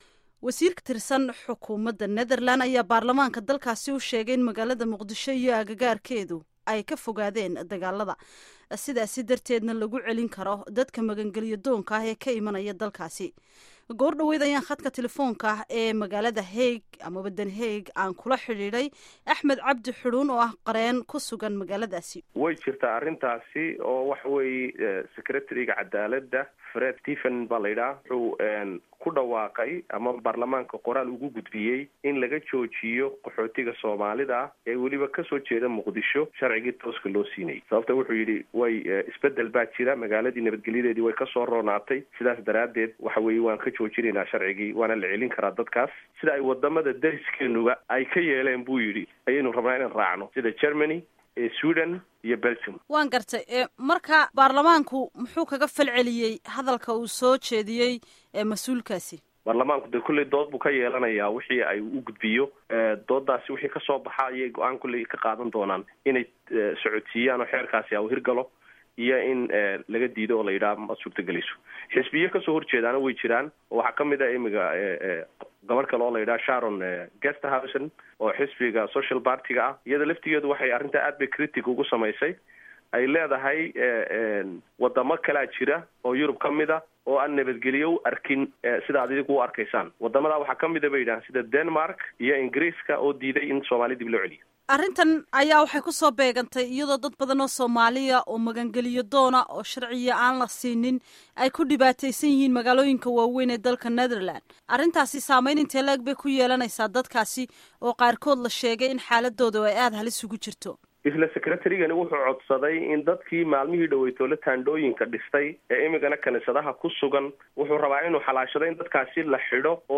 Wareysiga Qaxootiga Holland